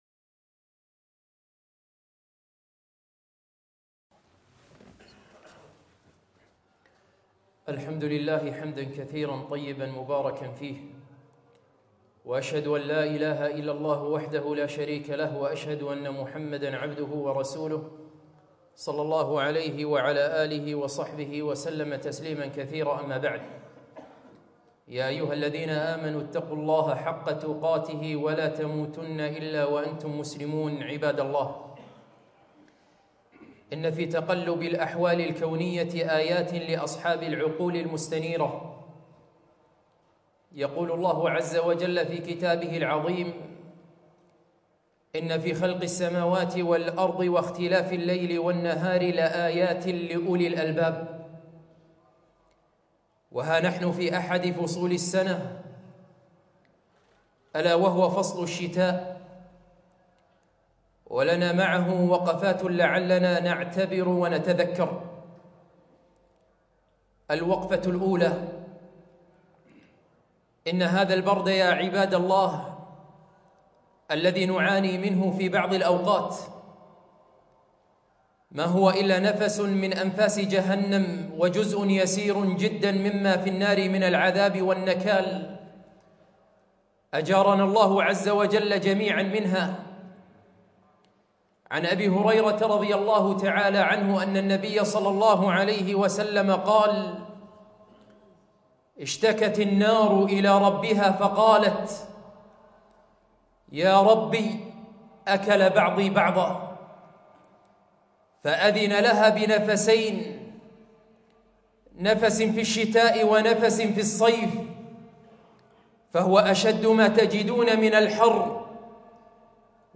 خطبة - ثلاث وقفات مع فصل الشتاء